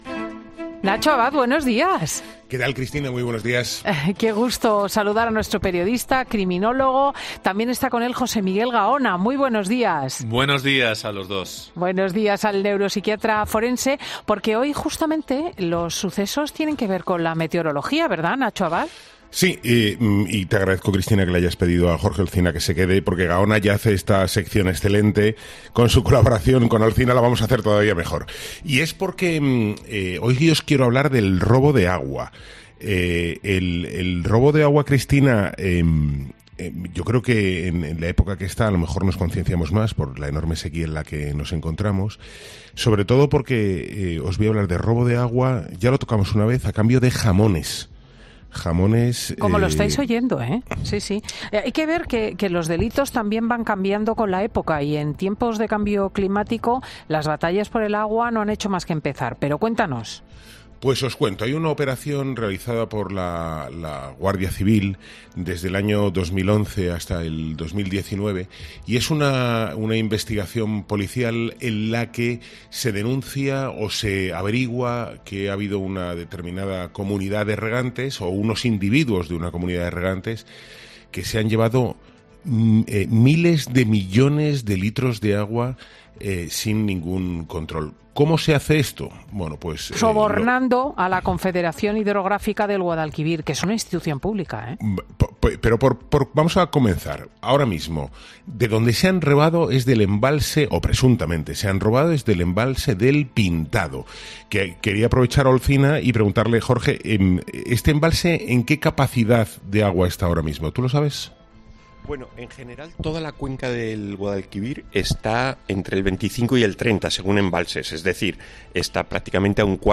Se oye como un hombre ordena a una mujer que cambie las cifras del contador del agua: "Modifica la lectura inicial".
Todo ello desató la risa de los colaboradores de Fin de Semana con Cristina López Schlichting, sorprendidos de la impunidad con la que actuaba. "Olé", respondieron al unísono entre risas.